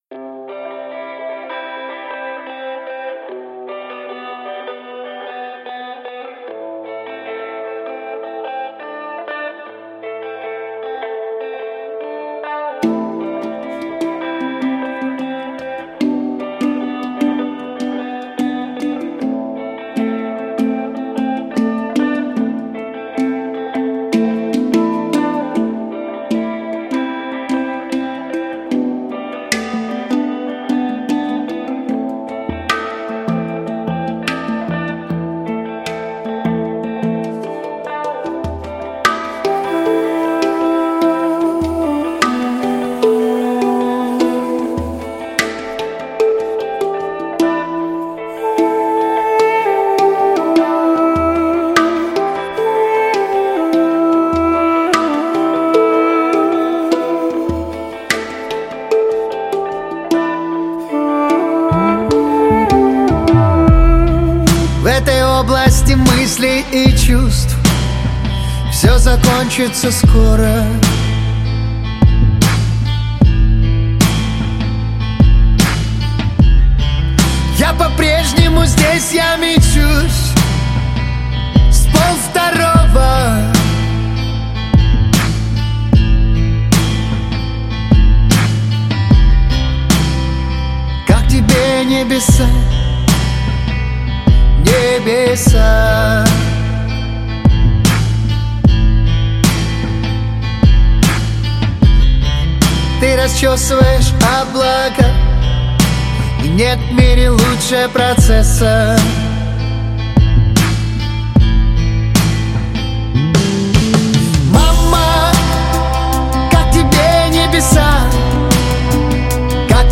Поп-музыка
Жанр: Жанры / Поп-музыка